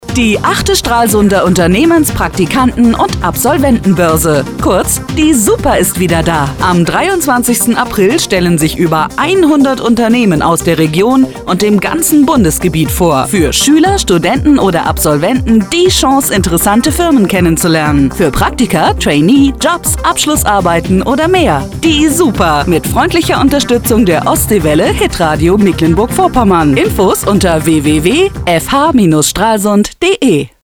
Ostseewelle Funk Spot